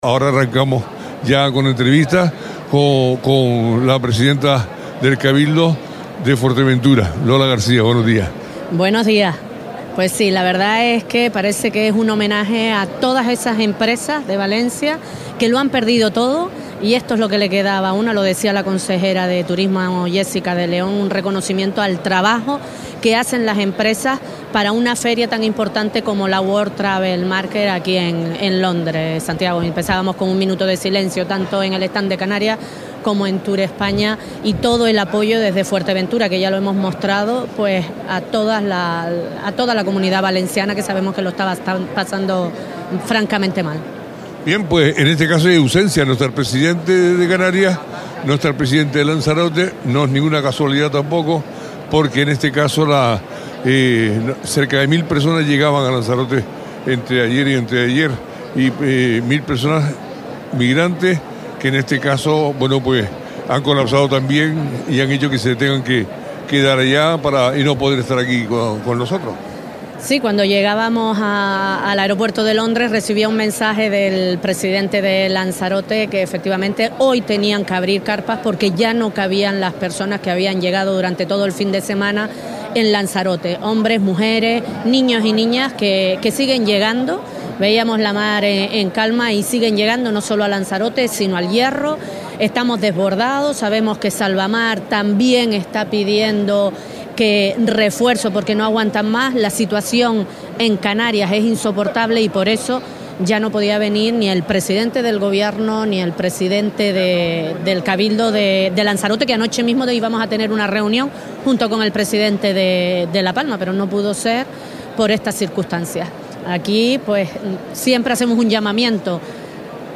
Lola García, presidenta del Cabildo de Fuerteventura, en la WTM
Entrevistas
Iniciamos la conexión desde la World Travel Market, desde Londres, junto a la presidenta del Cabildo de Fuerteventura, Lola García, quien ha trasladado la tristeza que se siente entre las persones que acuden a la feria desde Canarias y el resto de España debido a lo ocurrido en Valencia.